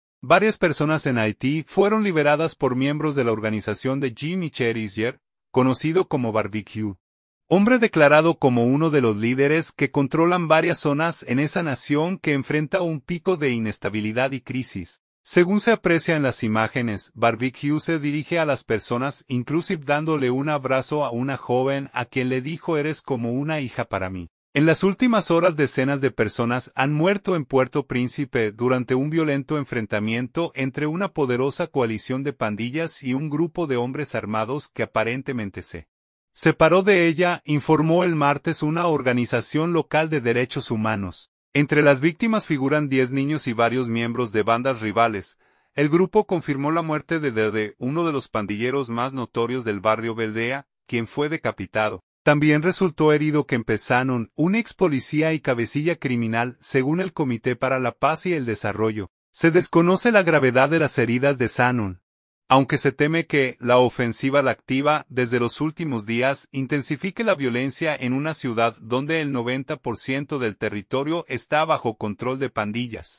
Según se aprecia en las imágenes, Barbecue se dirige a las personas inclusive dándole un abrazo a una joven a quien le dijo «eres como una hija para mi».